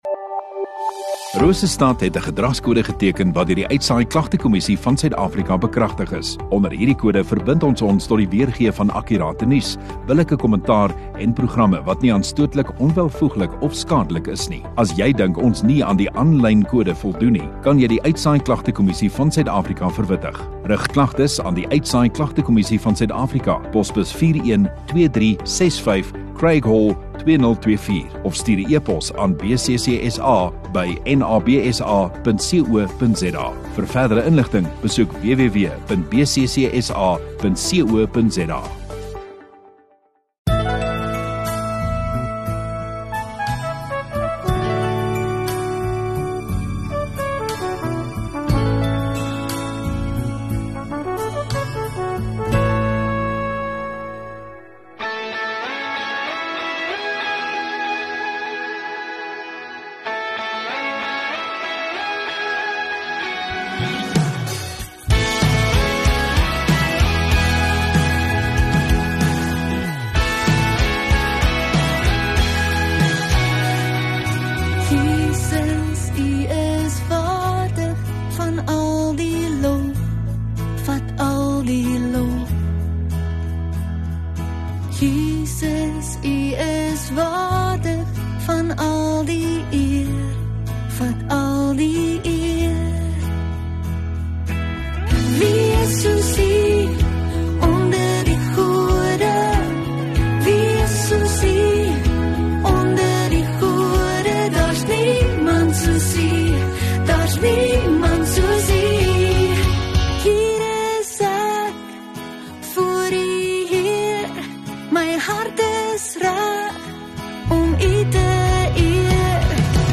10 Nov Sondagoggend Erediens